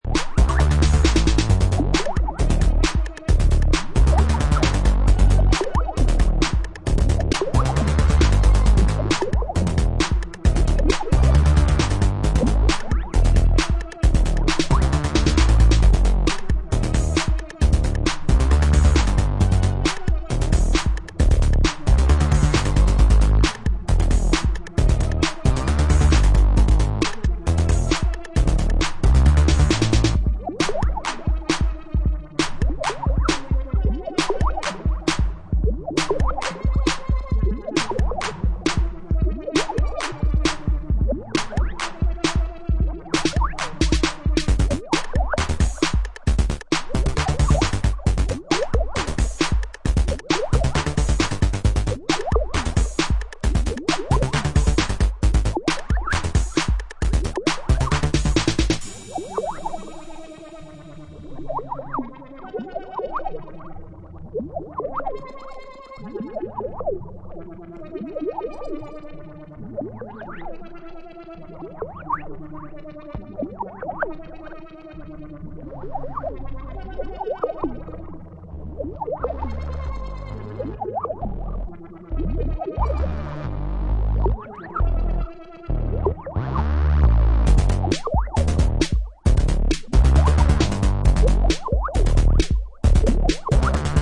With a classic feel throughout
crisp electro, through to smooth spacey acid
Electro Acid